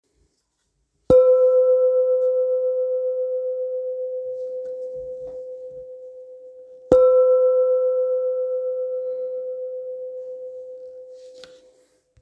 Kopre Singing Bowl, Buddhist Hand Beaten, Antique Finishing
Material Seven Bronze Metal
It is accessible both in high tone and low tone .
In any case, it is likewise famous for enduring sounds.